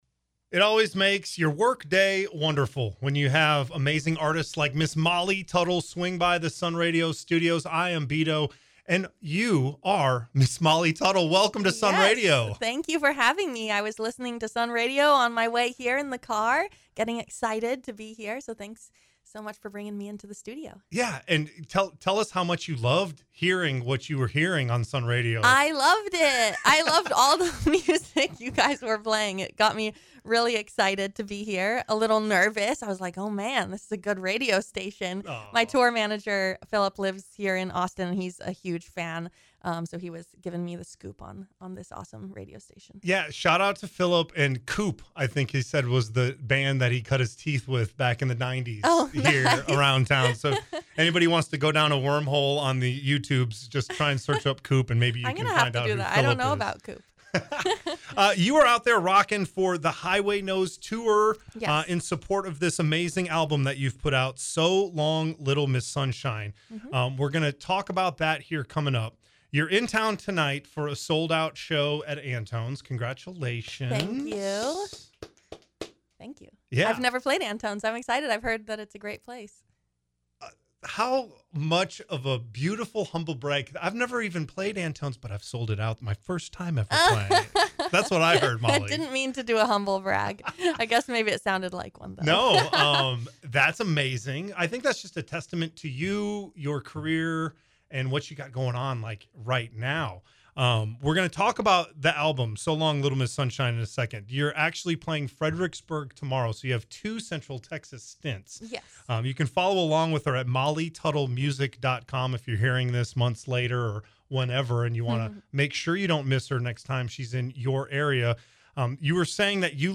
Molly Tuttle Interview
Molly Tuttle had a fantastic 2025 with the release of her album So Long Little Miss Sunshine. She took time to swing by the Sun Radio studio when she had a couple Central Texas tour dates.
molly-tuttle-interview.mp3